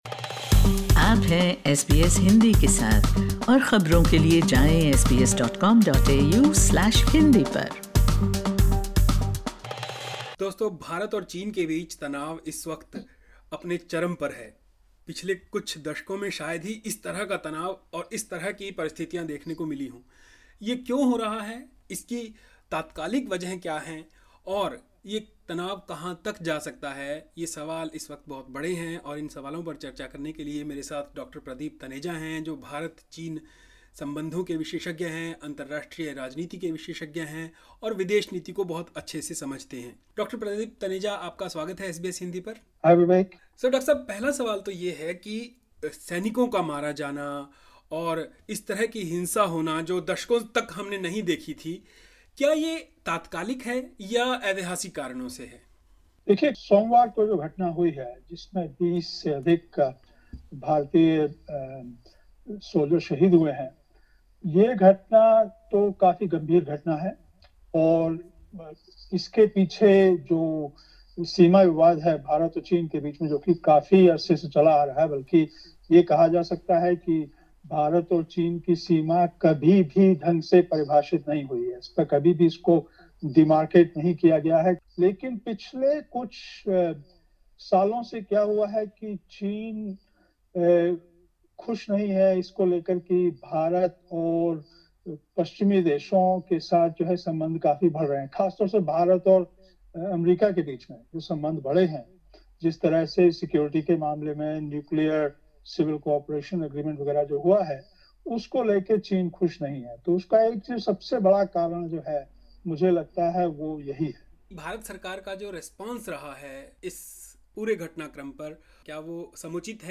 What are India's options? Listen to this interview: LISTEN TO Indo-China face-off: 'India's options are limited' says expert SBS Hindi 08:42 Hindi India's Prime Minister Mr Narendra Modi said India would give a befitting reply.